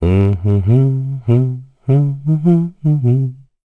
Kain-Vox_Hum_kr.wav